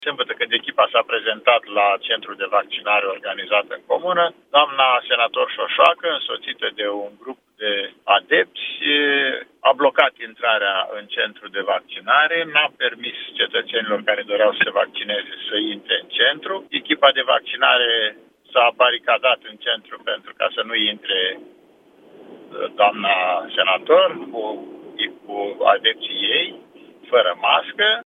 Directorul DSP Iași, Vasile Cepoi, a declarat la Europa FM că în ziua respectivă nu a reușit să se vaccineze nimeni în centrul respectiv: